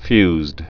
(fyzd)